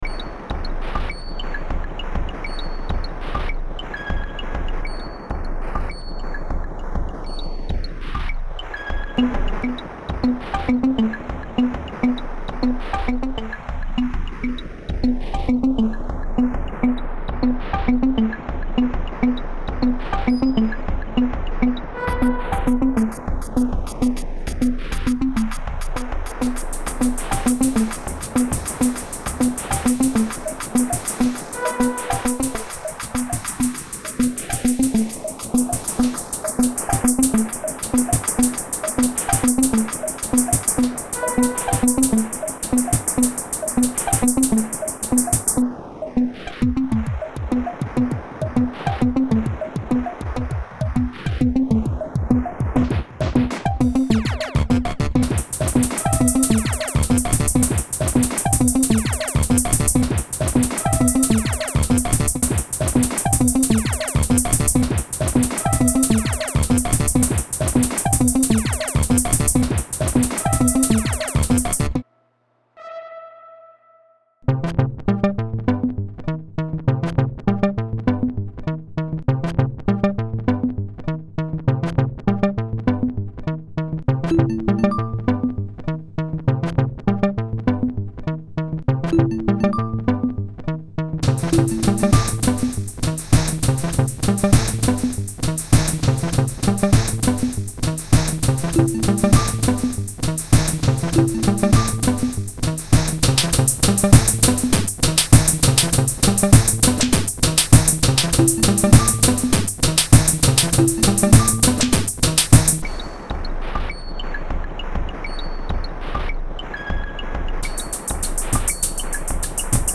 His music setup includes a midi step sequencer, midi mixer, sound module (see YouTube demo), and a vocal synthesizer that responds unpredictably to audio from the above gear.
I recorded some of his live-in-the-studio performance and made this track using editing software, effects software and virtual instruments. It is still a rough draft--I plan to make a few more tunes and then master them a little better.